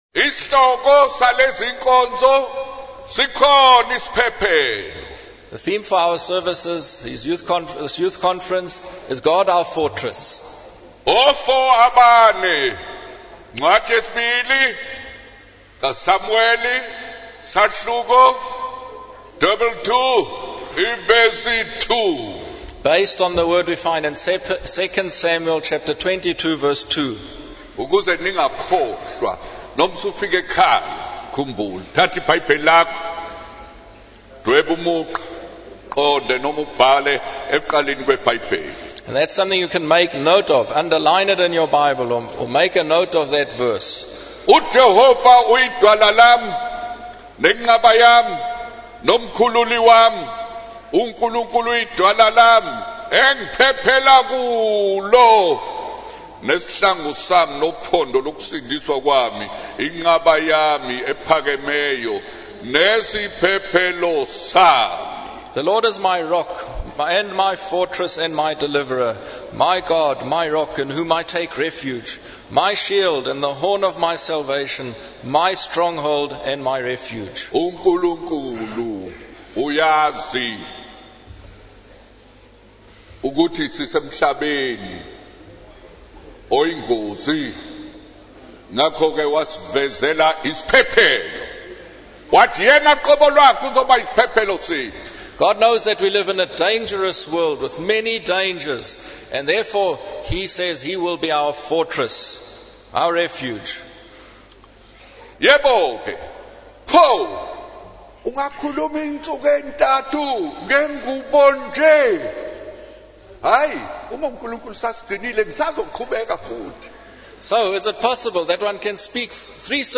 In this sermon, the minister shares a story about a young man who rejects laws and rules, believing that they restrict his freedom. However, the minister emphasizes the importance of laws and rules in maintaining order and preventing chaos.